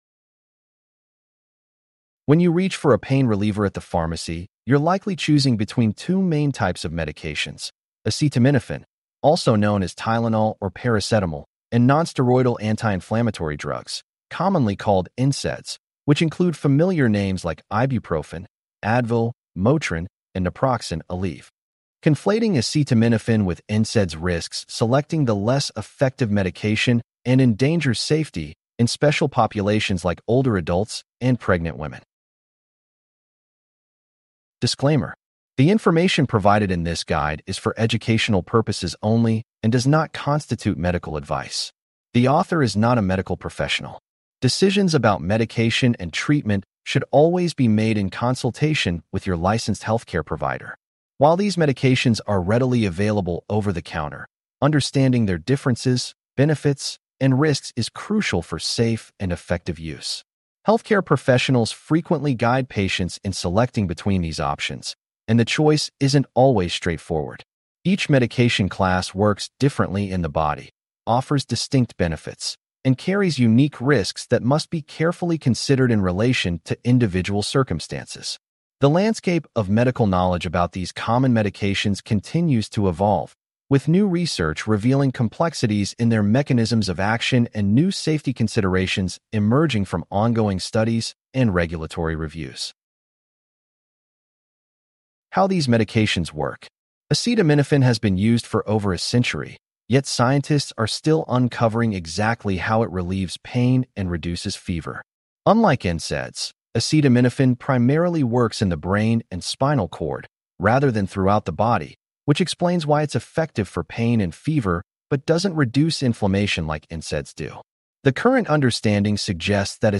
CLICK TO HEAR THIS POST NARRATED Conflating acetaminophen with NSAIDs risks selecting a less effective medication and endangers safety in special populations like older adults and pregnant mothers.